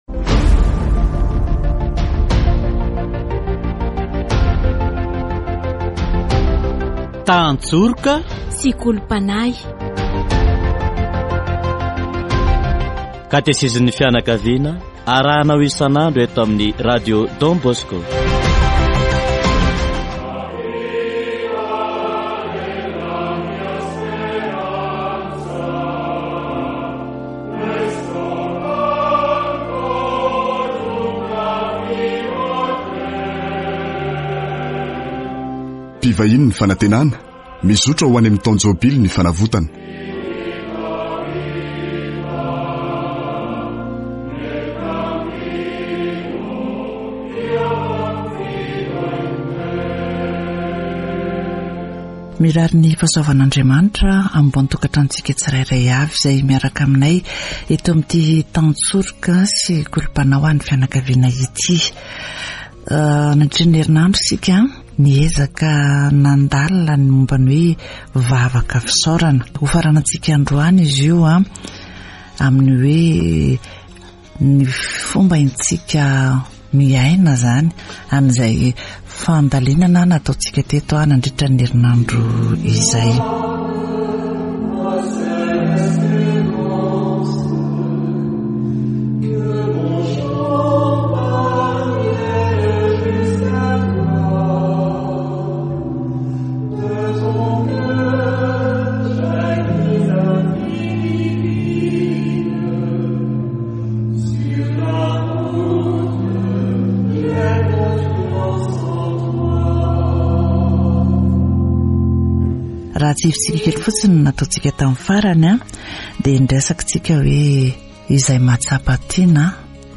Catéchèse sur la Prière d'action de grâce